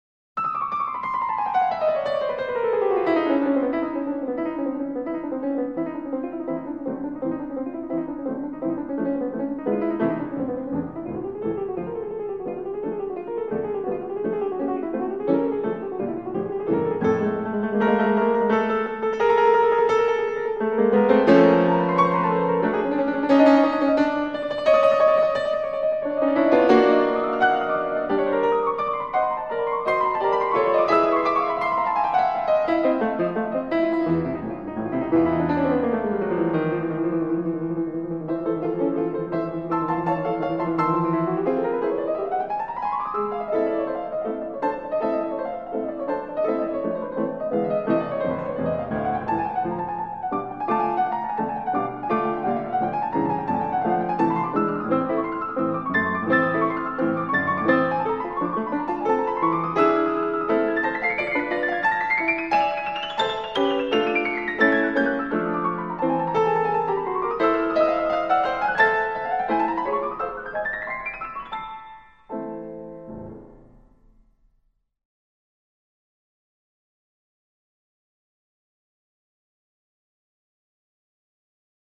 bumblebee